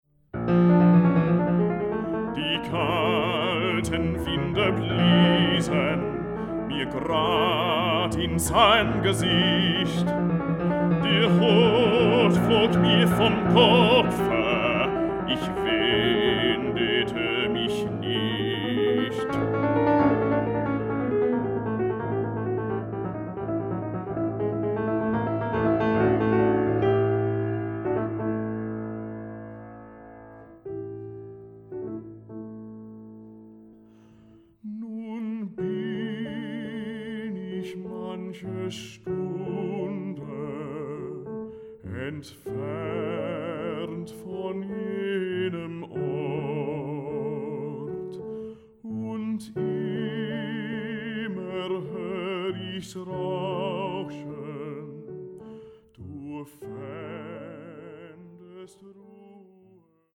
pianist
baritone
song cycles for solo voice with piano